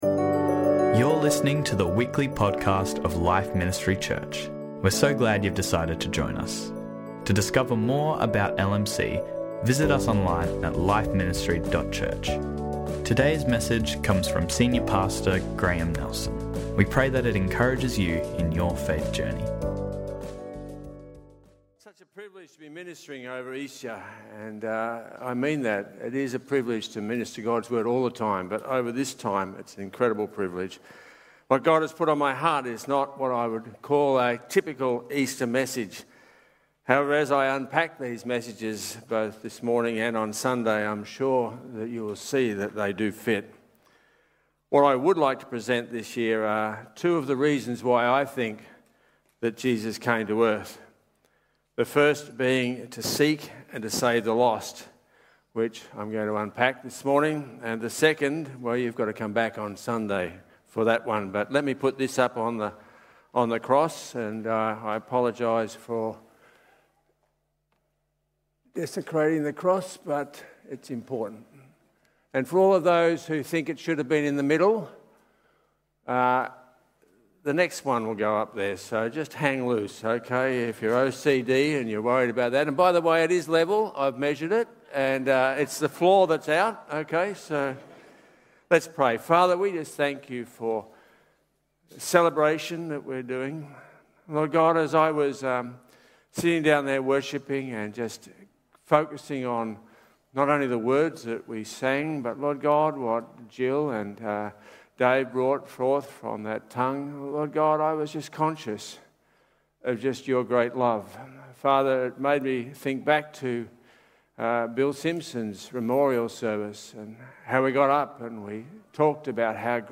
For this year's Good Friday message